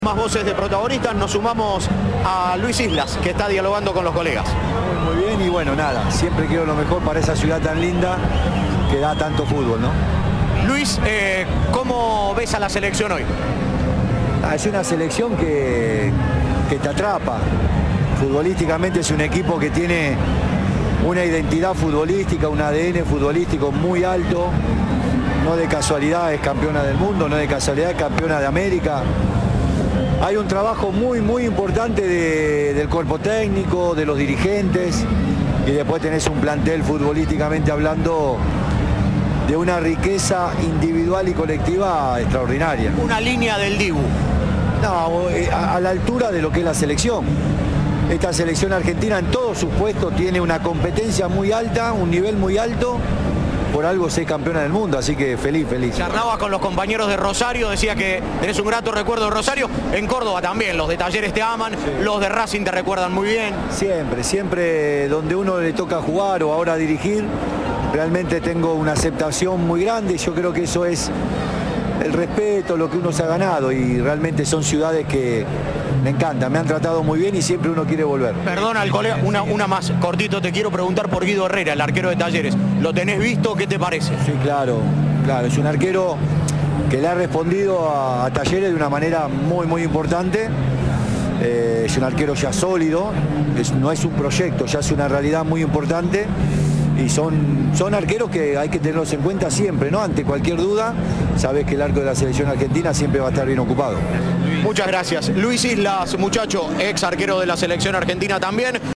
Luis Islas habló con Cadena 3 en la previa del partido.